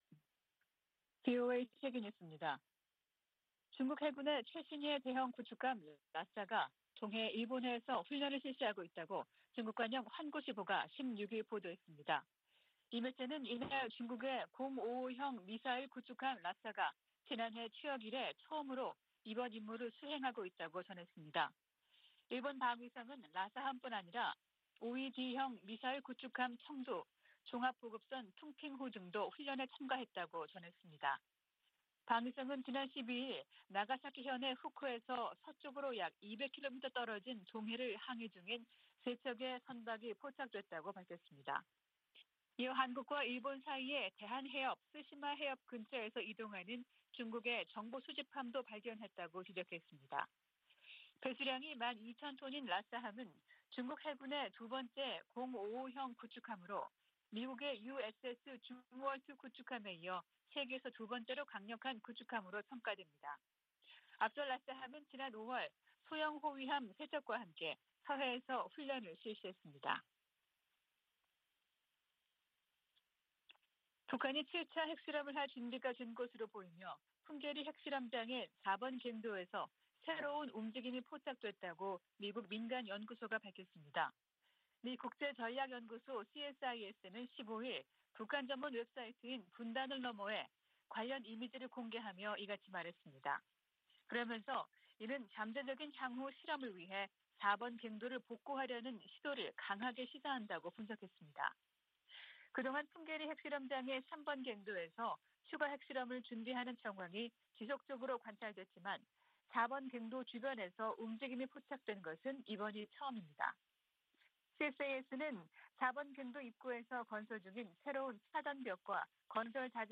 VOA 한국어 아침 뉴스 프로그램 '워싱턴 뉴스 광장' 2022년 6월 17일 방송입니다. 미 하원 세출위원회 국방 소위원회가 북한 관련 지출을 금지하는 내용을 담은 2023 회계연도 예산안을 승인했습니다. 북한 풍계리 핵실험장 4번 갱도에서 새로운 움직임이 포착됐다고 미국의 민간연구소가 밝혔습니다. 북한이 지난해 핵무기 개발에 6억4천200만 달러를 썼다는 추산이 나왔습니다.